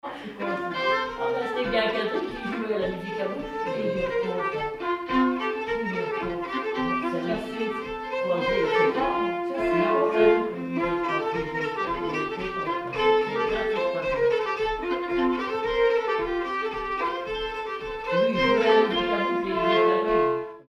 Quadrille
danse : quadrille
circonstance : bal, dancerie
Pièce musicale inédite